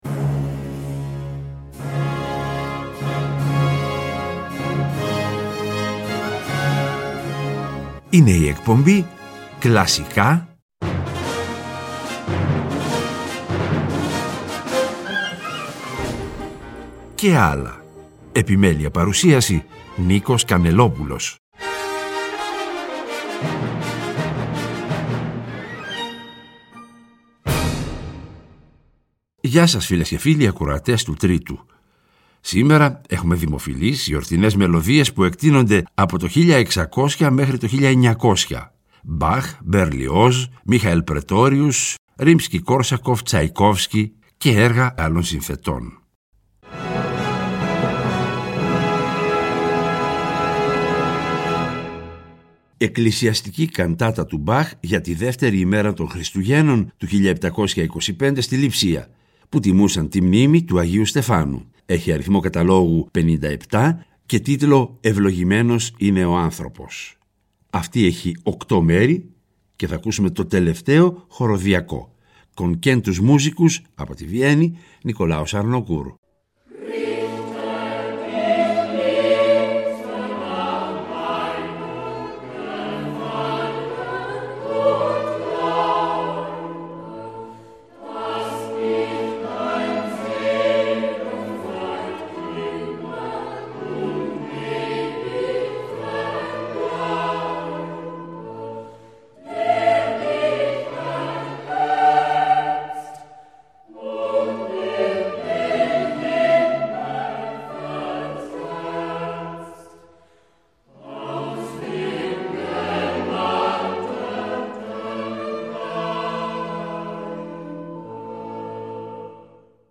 Δημοφιλείς, γιορτινές μελωδίες που εκτείνονται από το 1600 έως το 1900.